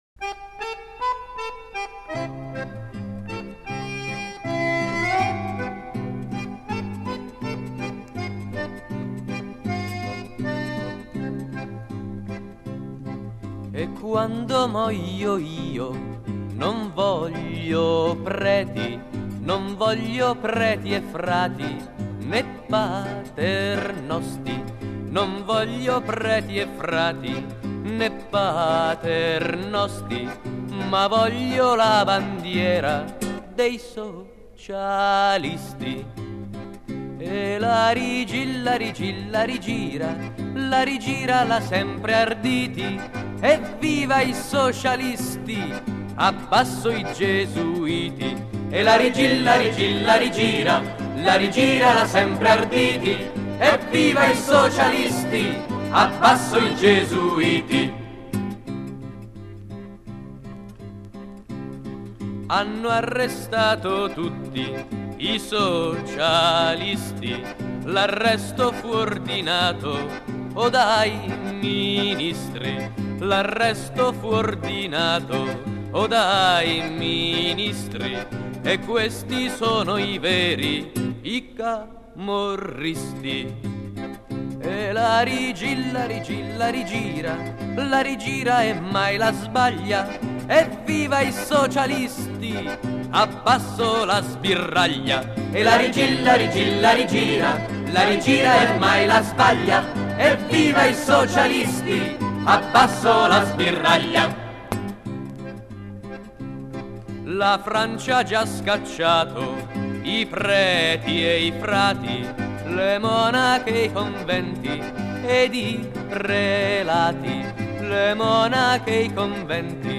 Poi alla fine si può sfogare coi canti sociali: sicuramente risuonerà l’immortale stornello “Vorrei che l’Vaticano andesse in fiamme” seguito da
stornellianticlericali.mp3